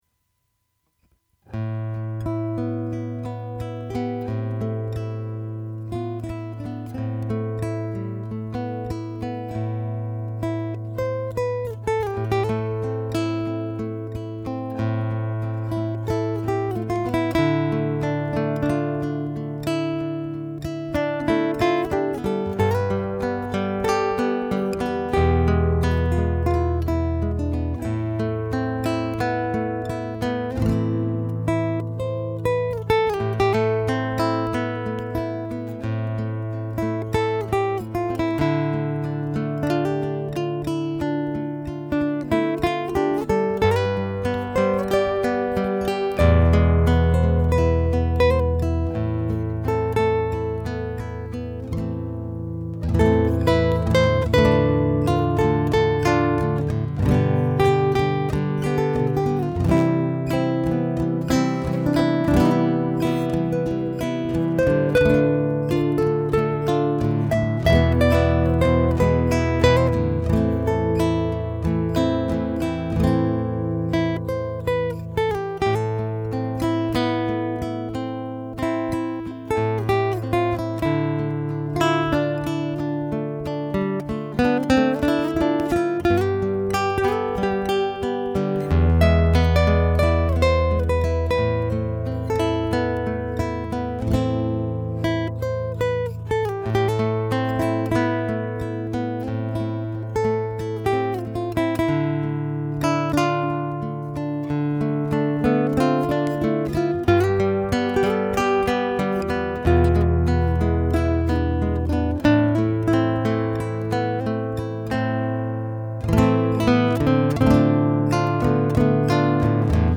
classical_wikiloops_jam_no_14862.mp3